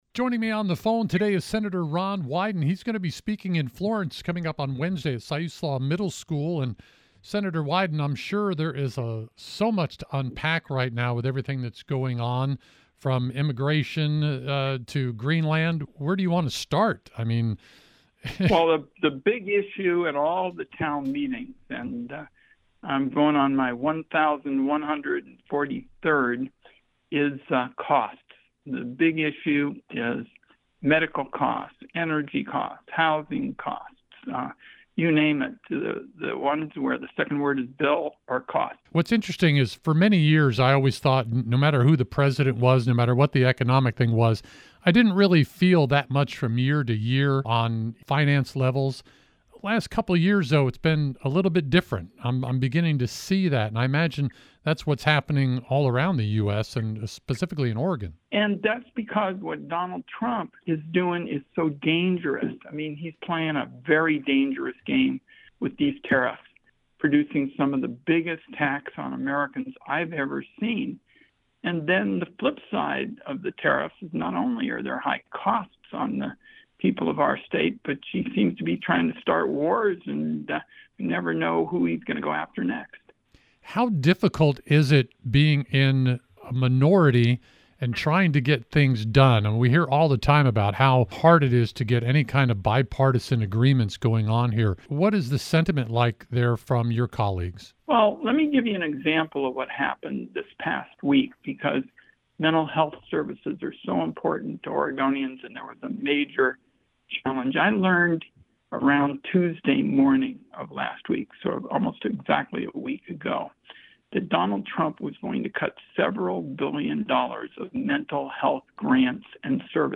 Senator Ron Wyden Speaks to Coast Radio Ahead of Wednesday’s Visit